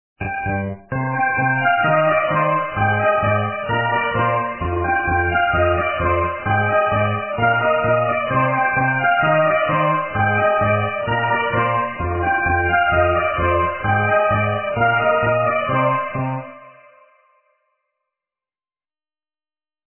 - русская эстрада
качество понижено и присутствуют гудки